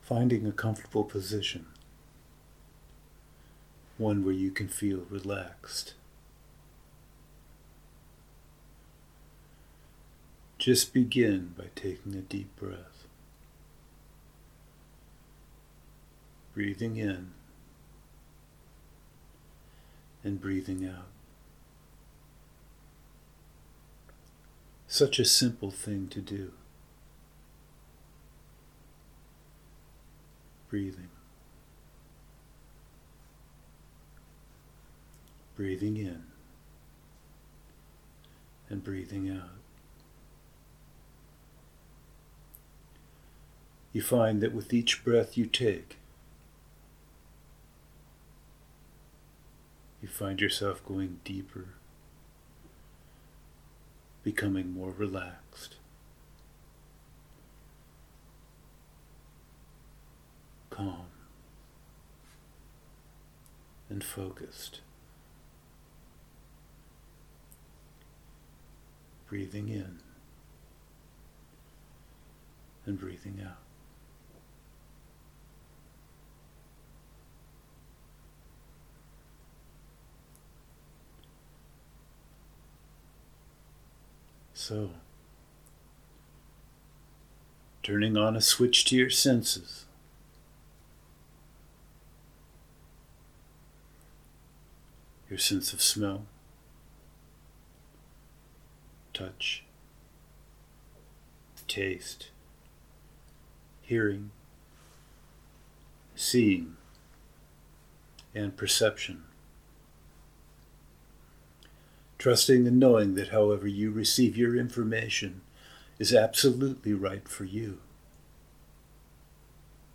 Corona self-quarantine-English Meditation